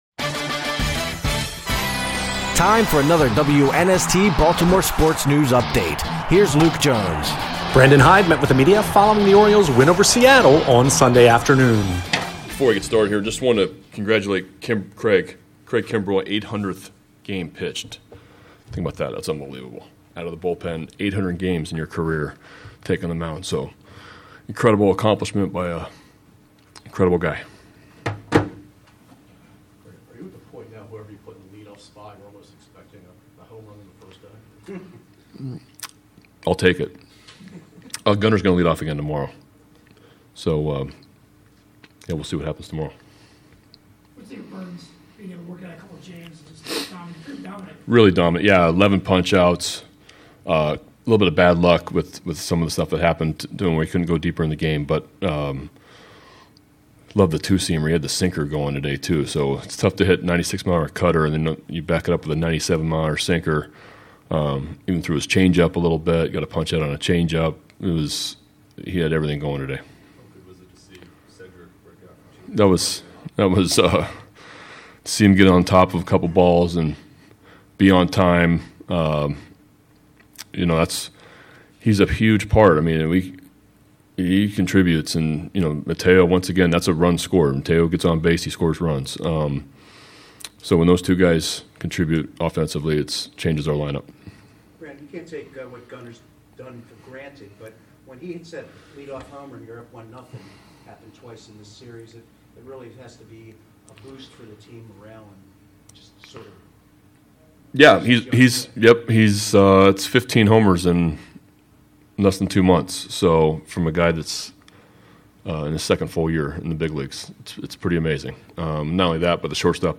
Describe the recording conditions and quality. Locker Room Sound